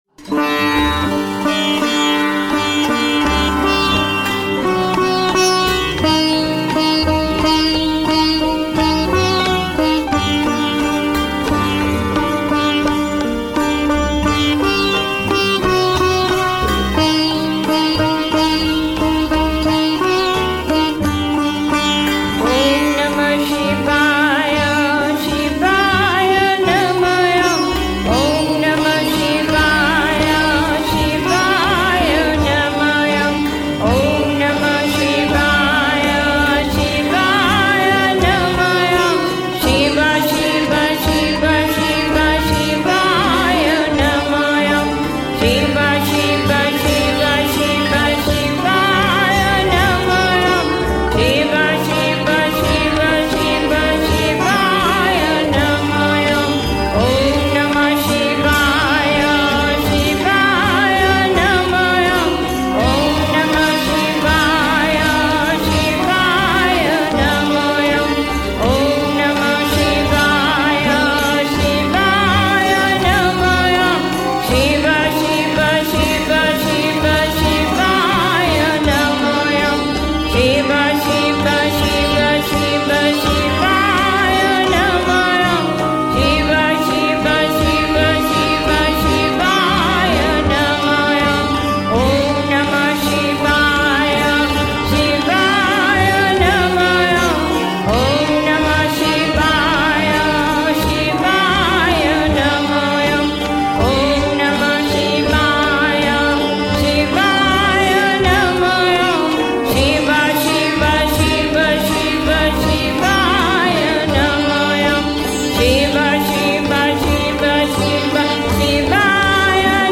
Melodic, soothing, and very powerful.